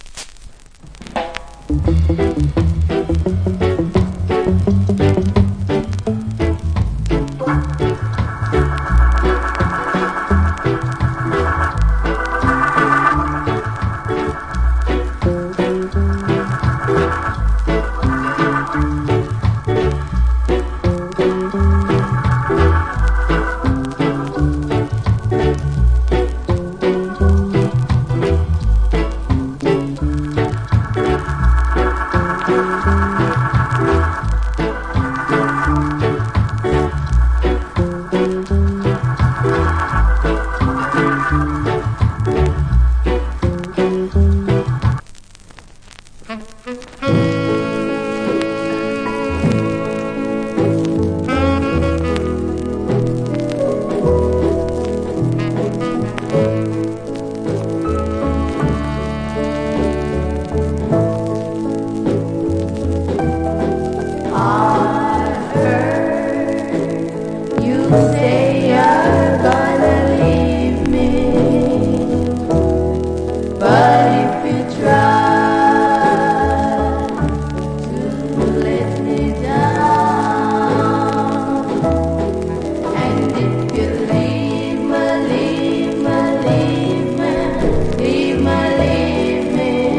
Nice Organ Rock Steady Inst.